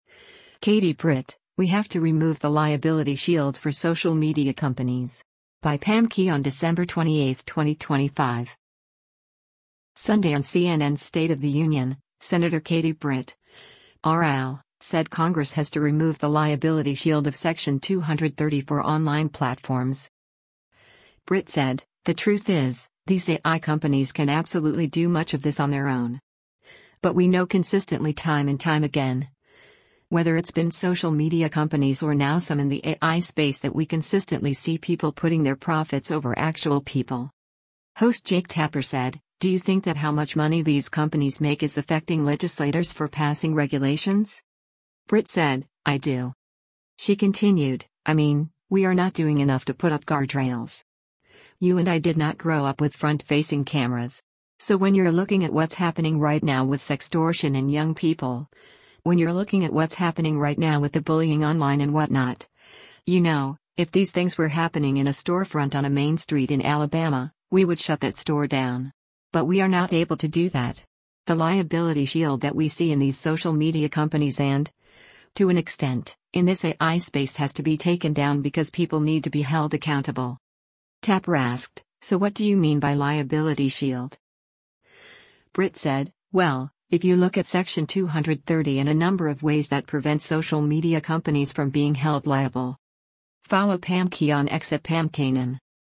Sunday on CNN’s “State of the Union,” Sen. Katie Britt (R-AL) said Congress has to remove the “liability shield” of Section 230 for online platforms.